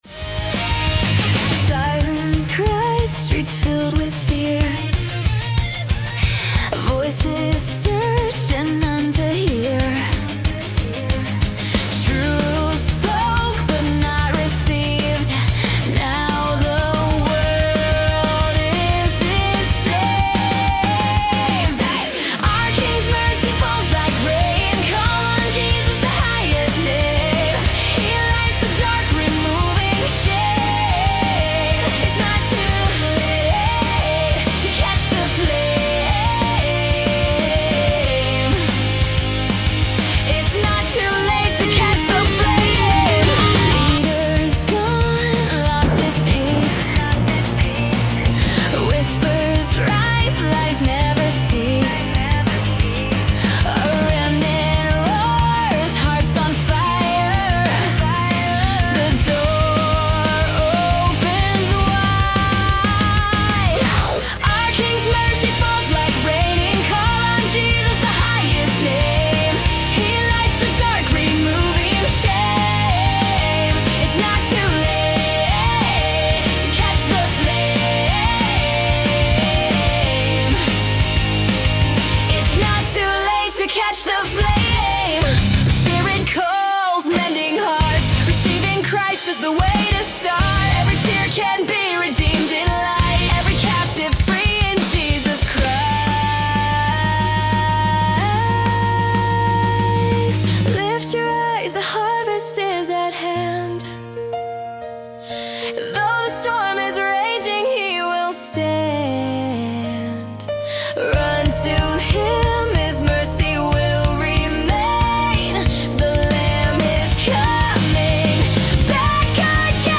• Genre: Christian Rock / Worship Rock